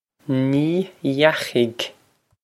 Ní dheachaigh Nee yakh-ig
This is an approximate phonetic pronunciation of the phrase.